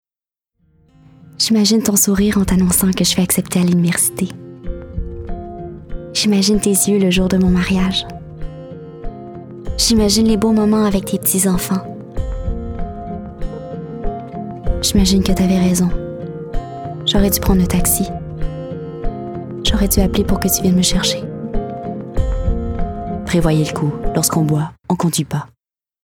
/wp-content/uploads/2014/05/alcool.mp3 Publicité: Alcool au volant /wp-content/uploads/2014/05/miammiao.mp3 Publicité nourriture pour chant: Miammiao /wp-content/uploads/2014/05/atakov.mp3 Publicité boisson alcoolisée: Atakov